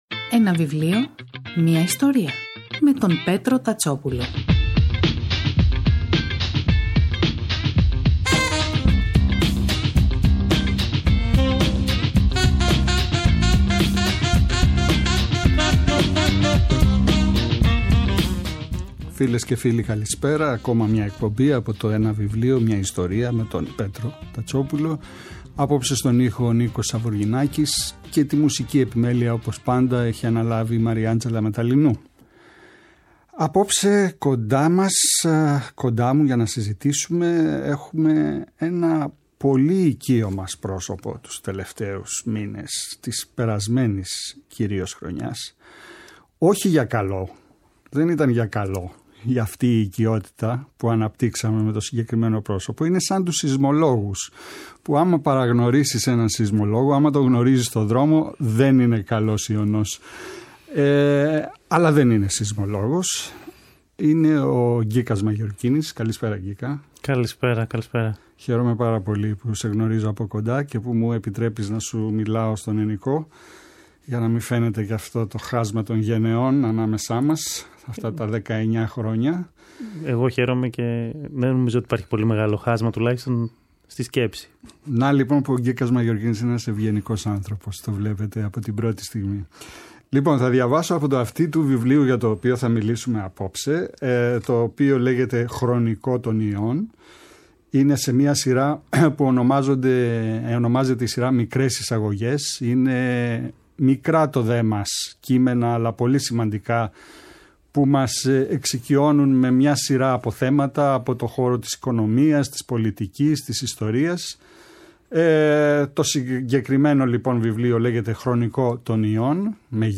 Η εκπομπή “Ένα βιβλίο, μια ιστορία” του Πέτρου Τατσόπουλου, κάθε Σάββατο και Κυριακή, στις 5 το απόγευμα στο Πρώτο Πρόγραμμα της Ελληνικής Ραδιοφωνίας παρουσιάζει ένα συγγραφικό έργο, με έμφαση στην τρέχουσα εκδοτική παραγωγή, αλλά και παλαιότερες εκδόσεις.